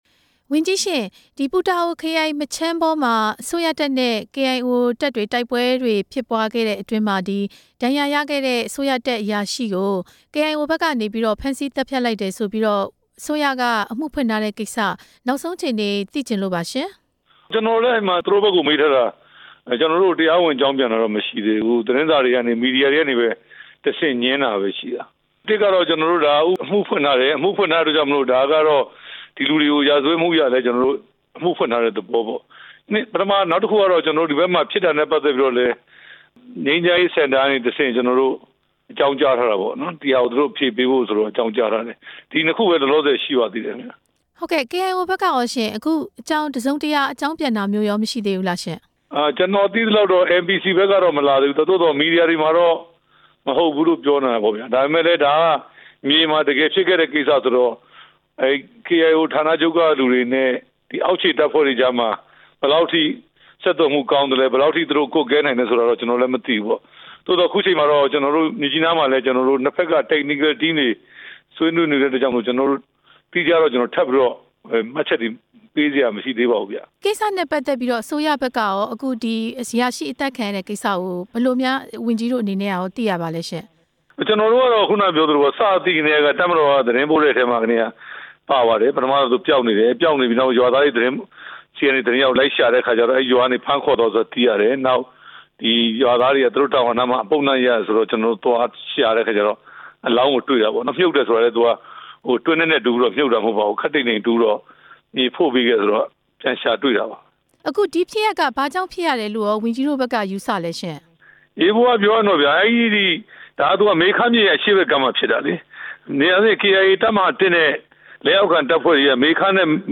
တပ်မတော်အရာရှိ သတ်ဖြတ်ခံရမှု သမ္မတပြောခွင့်ရပုဂ္ဂိုလ်နဲ့ ဆက်သွယ်မေးမြန်းချက်